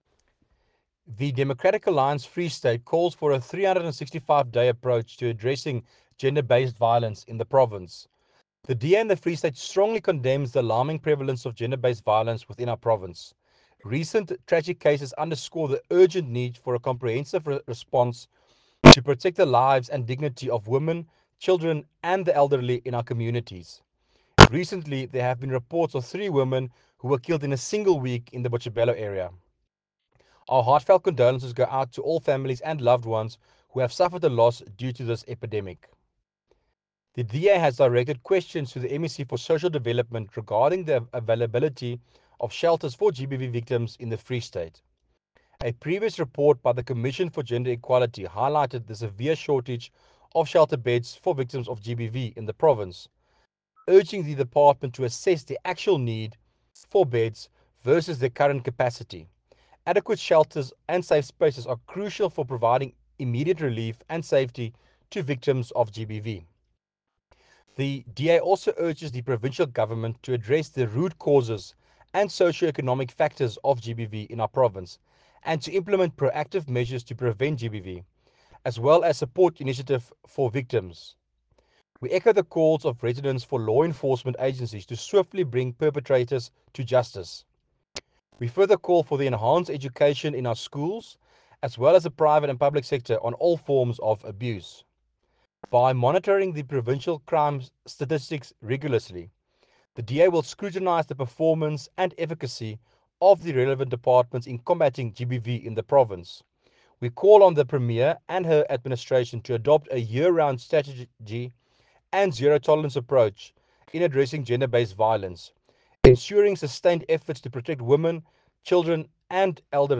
Issued by Werner Pretorius – DA Member of the Free State Provincial Legislature
Afrikaans soundbites by Werner Pretorius MPL